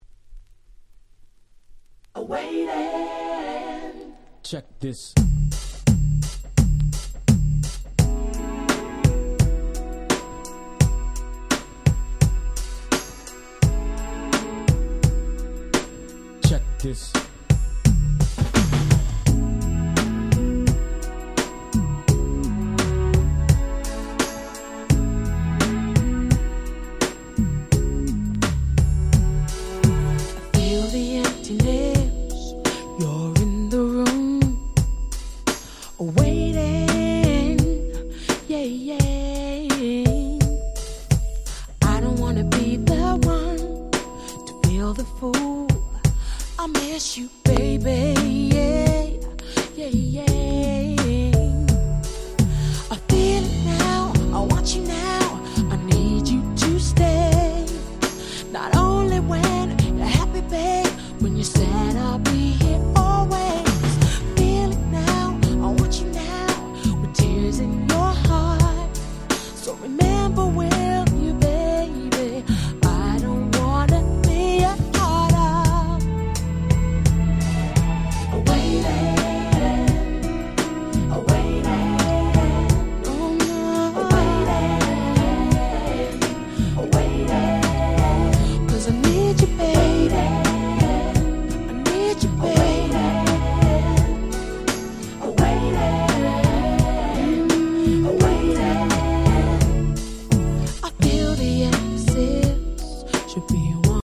Nice UK R&B EP !!
Acid Jazz アシッドジャズ